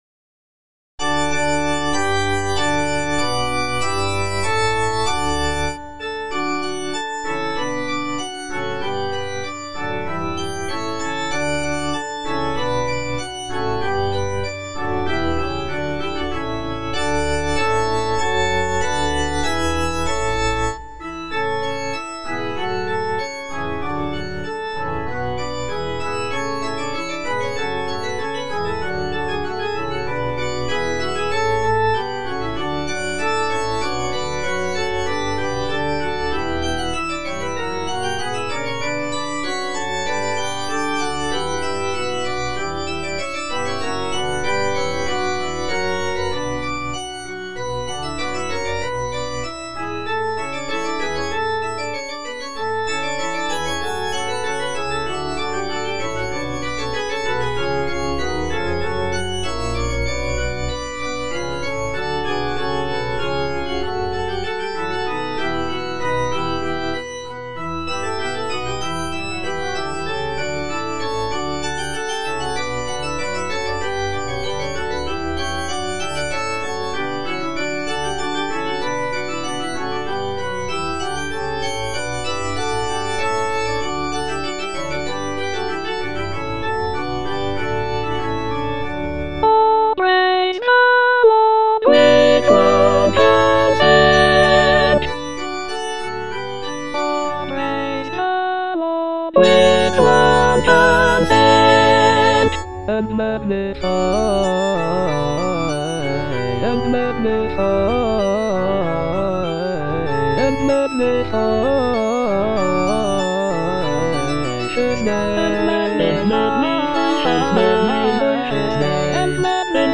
Choralplayer playing O praise the Lord with one consent - Chandos anthem no. 9 HWV254 (A = 415 Hz) by G.F. Händel based on the edition CPDL #08760
G.F. HÄNDEL - O PRAISE THE LORD WITH ONE CONSENT - CHANDOS ANTHEM NO.9 HWV254 (A = 415 Hz) O praise the Lord - Soprano (Emphasised voice and other voices) Ads stop: auto-stop
Written in 1717, this piece features grand choral writing, intricate counterpoint, and expressive solos for singers.